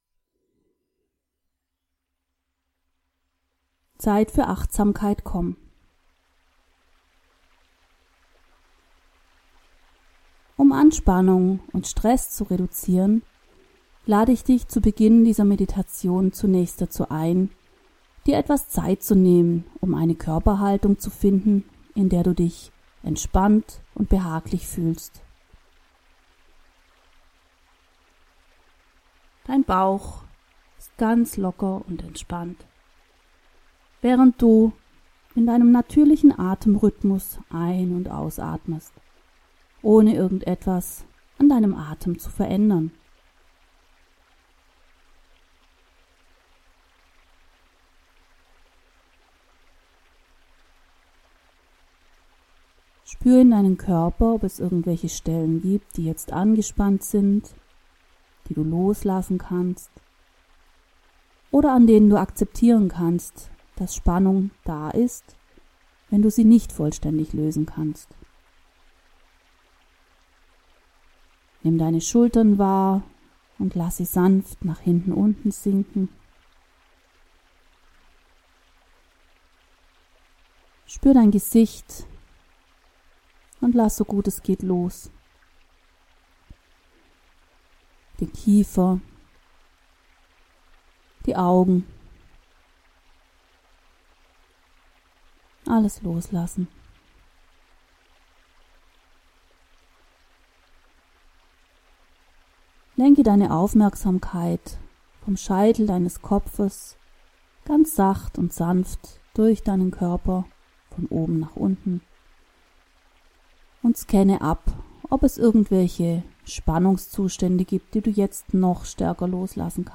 Meditation „Entspannung mit dem Atem“
mit_dem_Atem_entspannen-2.mp3